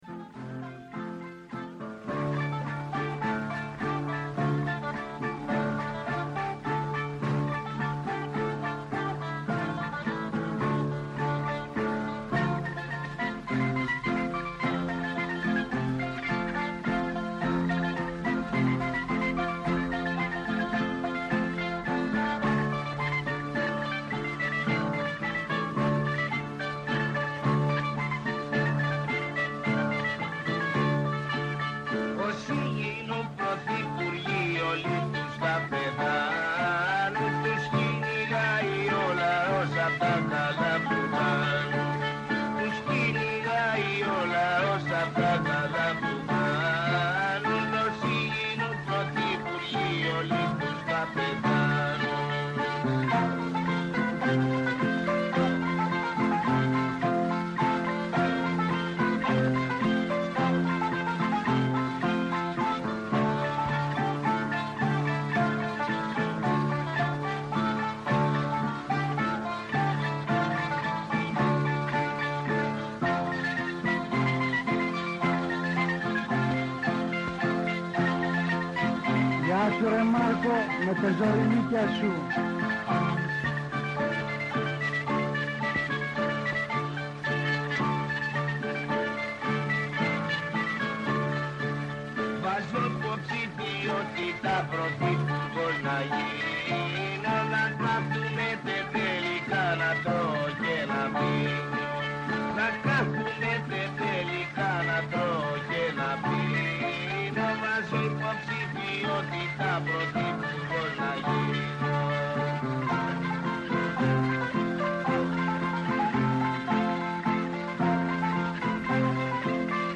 Καλεσμένοι τηλεφωνικά στην σημερινή εκπομπή:
-ο Νίκος Βούτσης, πρώην Πρόεδρος της Βουλής, υποψήφιος βουλευτής ΣΥΡΙΖΑ-ΠΣ στην Α’ Αθηνών